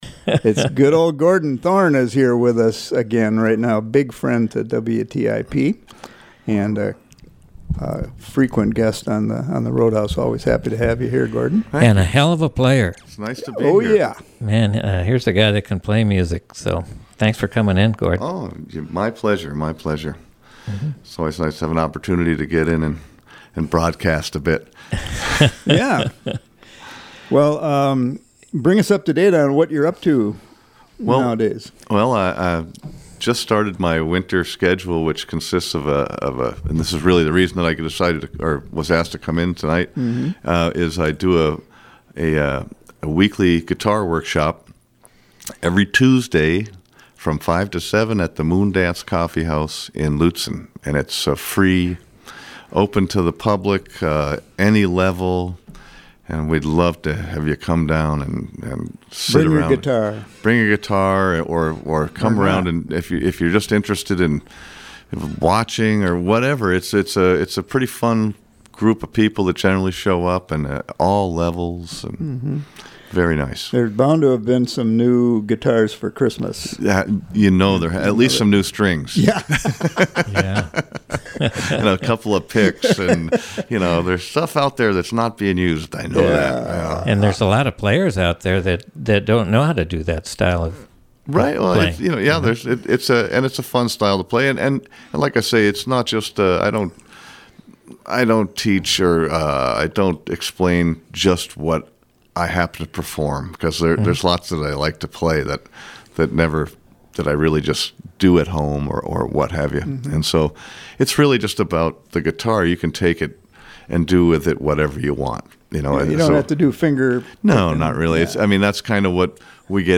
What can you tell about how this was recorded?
Program: Live Music Archive The Roadhouse